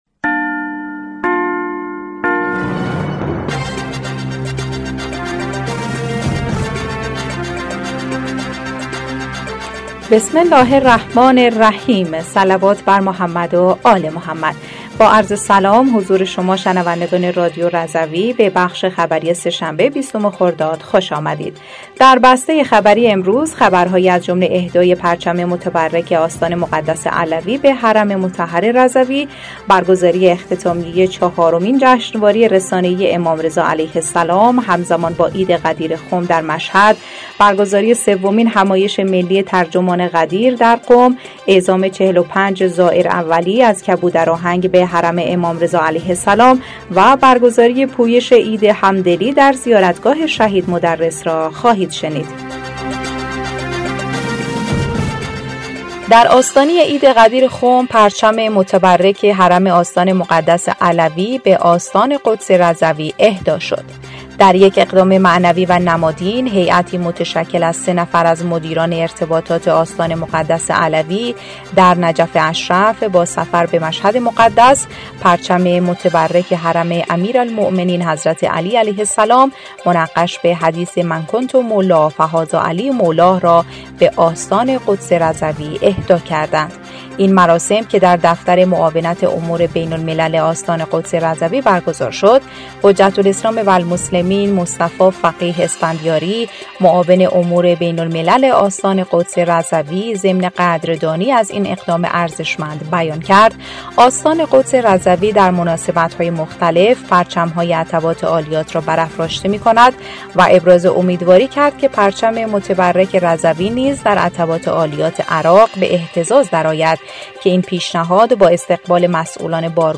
بسته خبری ۲۰ خردادماه ۱۴۰۴ رادیو رضوی/